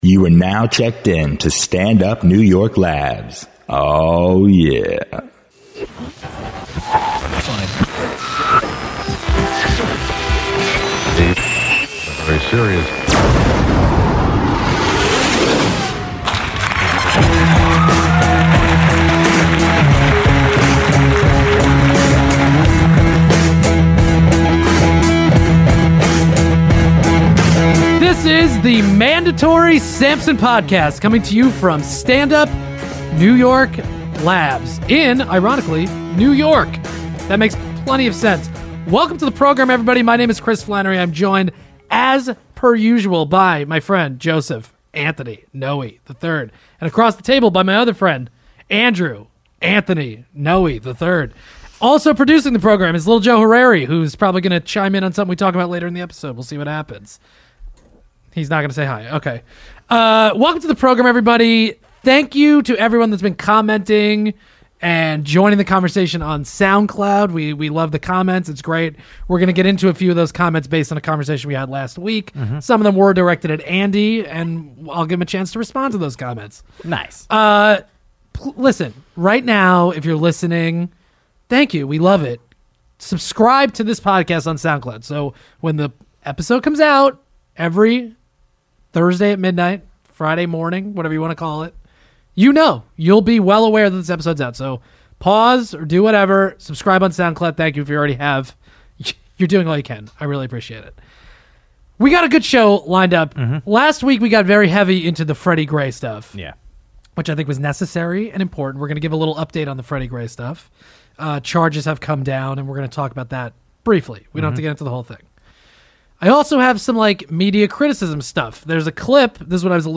back in studio